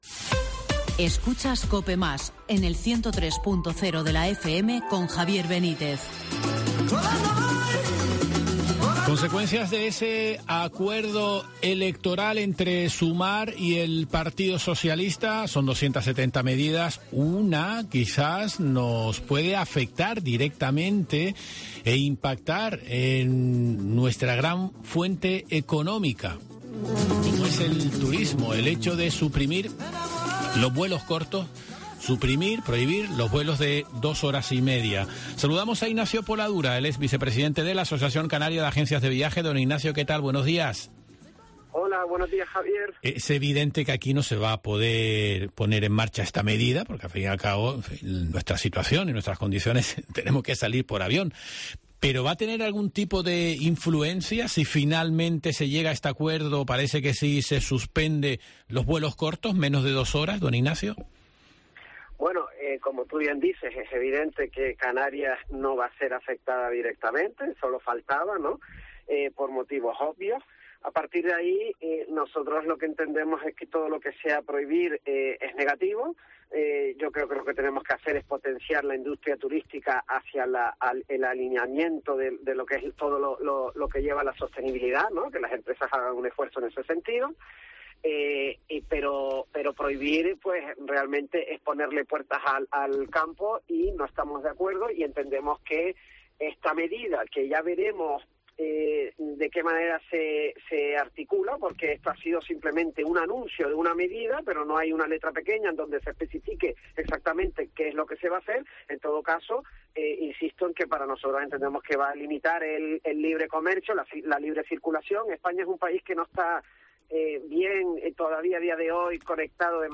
Durante la entrevista también pone el foco en el aumento de las tasas aeroportuarias.